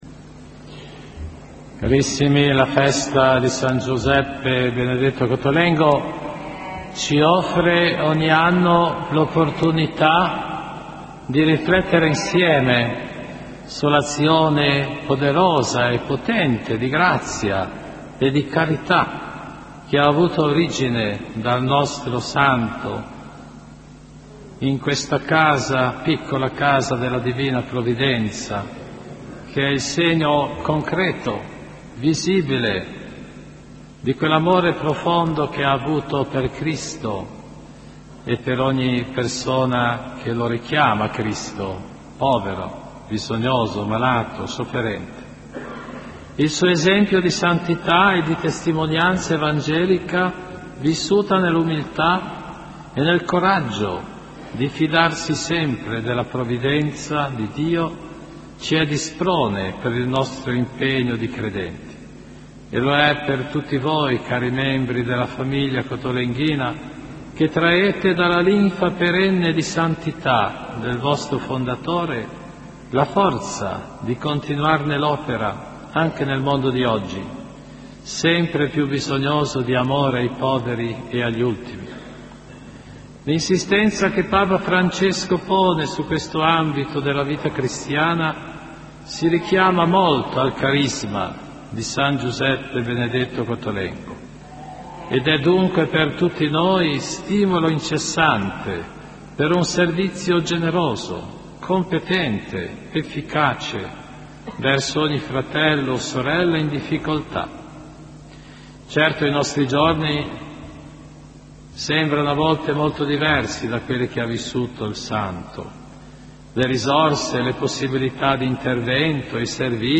Omelia di mons. Cesare Nosiglia per la festa di San Giuseppe Benedetto Cottolengo, Torino 30 aprile 2014 (mp3, 3 Mb)